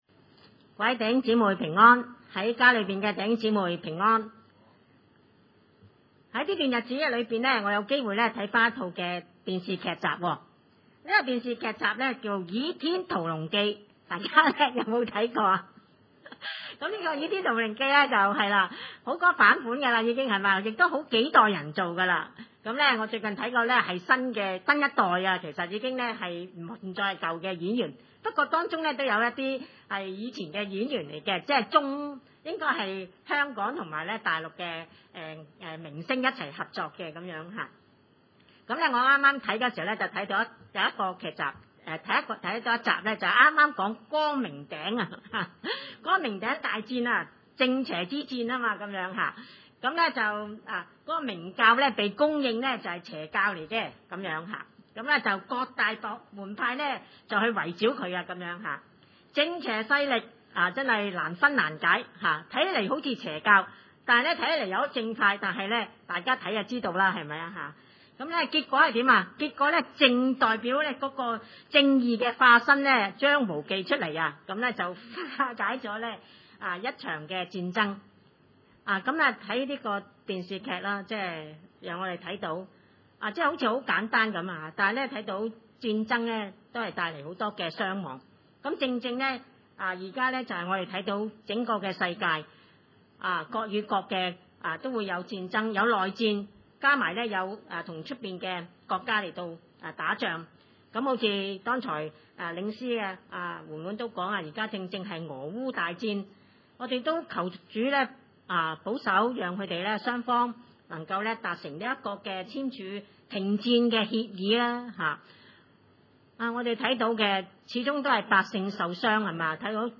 經文: 列王紀上︰十八 20-39 崇拜類別: 主日午堂崇拜 20亞哈就派人到以色列眾人那裏，召集先知上迦密山。